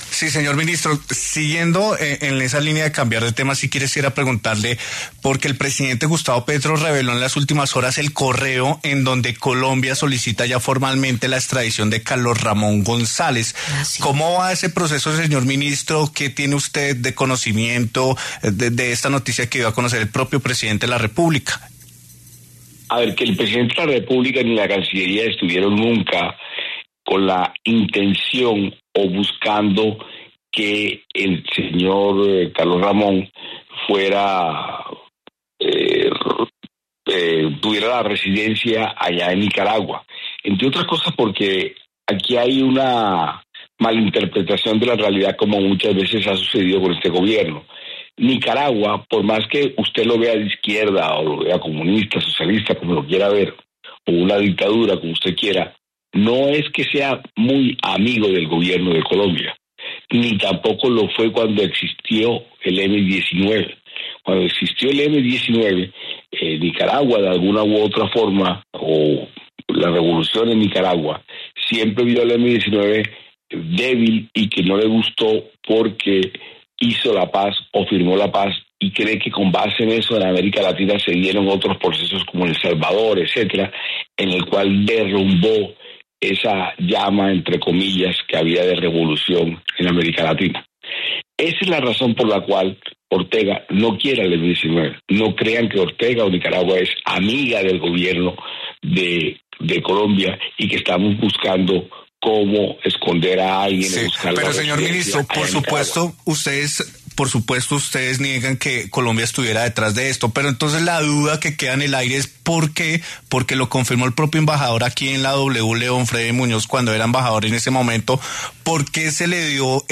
Armando Benedetti, ministro del Interior, se refirió en La W a la polémica sobre la Embajada de Colombia en Nicaragua, por presuntamente haber facilitado la residencia de Carlos Ramón González -prófugo de la justicia- en ese país.
En diálogo con La W, el ministro del Interior, Armando Benedetti, se pronunció sobre la revelación del presidente Gustavo Petro del correo mediante el cual Colombia solicitó formalmente la extradición de Carlos Ramón González desde Nicaragua.